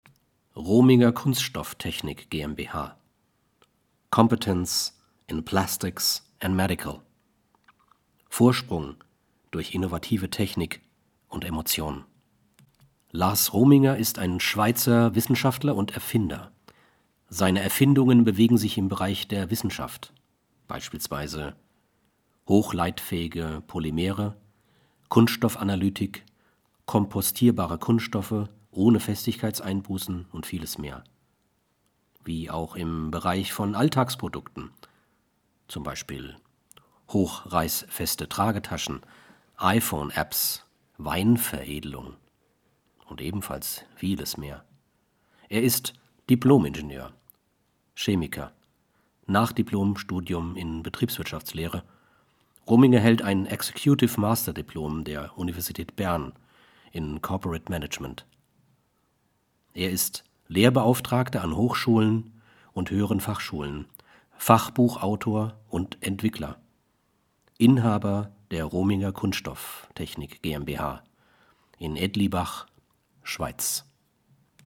Deutscher Sprecher, tief, Kinderbuch, Sachtext, Voice-Over, Lyrik, Roman, Vorleser Arne Dahl, MP3-Home-Production (ZOOM), 6 deutsche Dialekte
Sprechprobe: Industrie (Muttersprache):